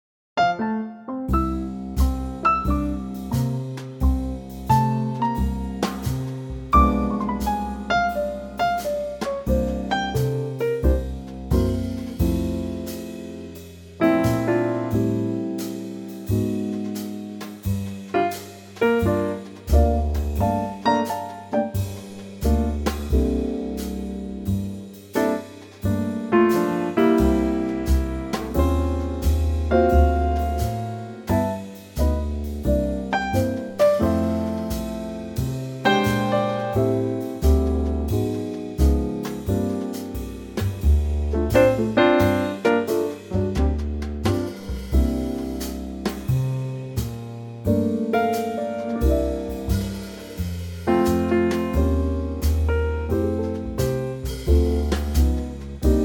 Unique Backing Tracks
key - F - vocal range - C to E